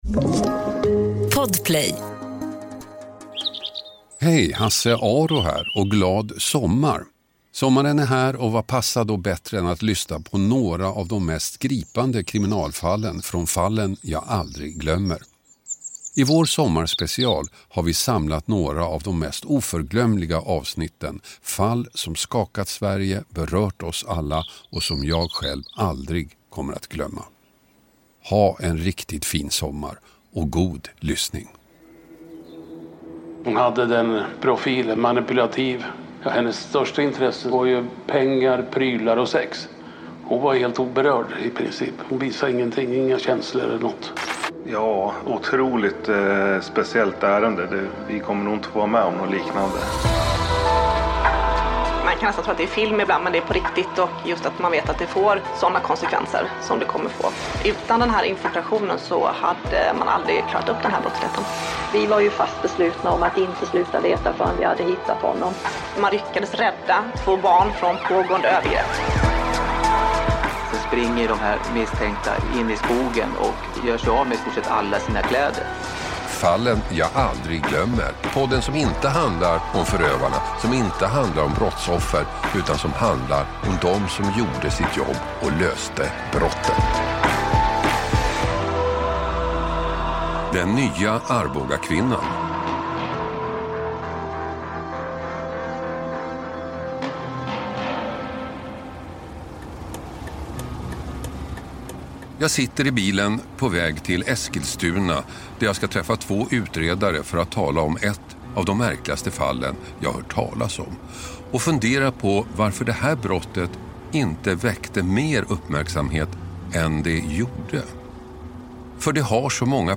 Hasse Aro intervjuar utredarna